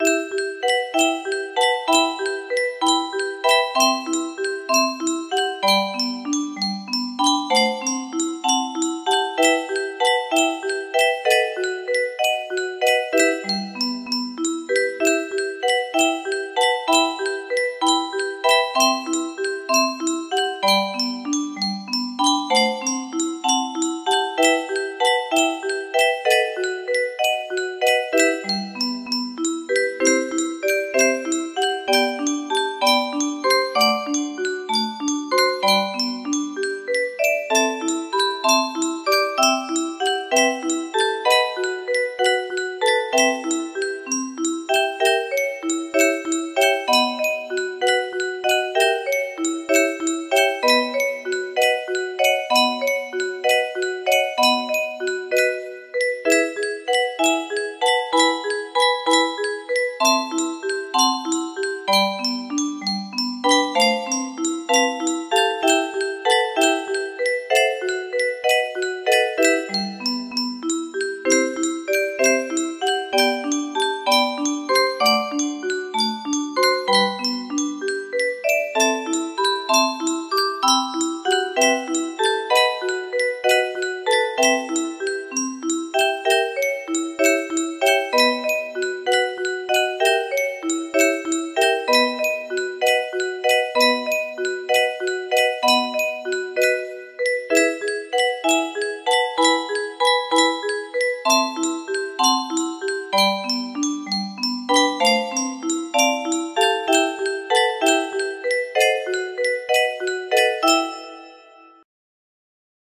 Die Moldau music box melody